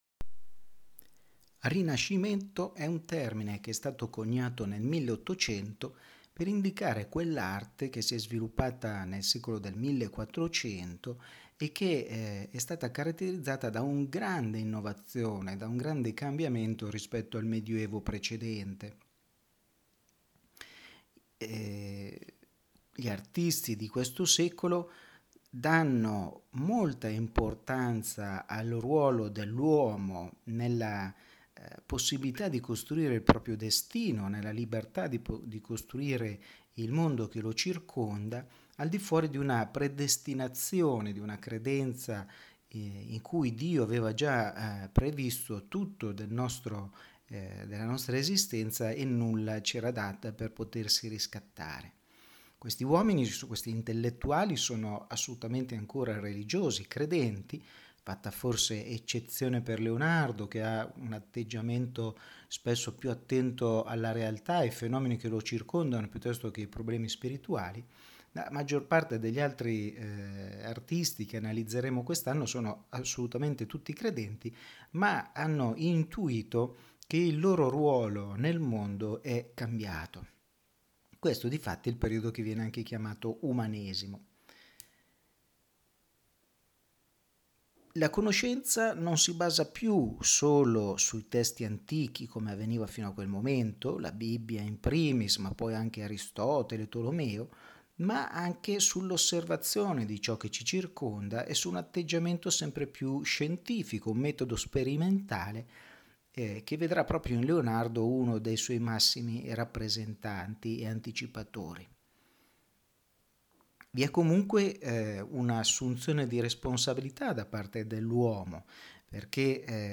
Ascolta la lezione audio dedicata al Rinascimento.
rinascimento introduzione.mp3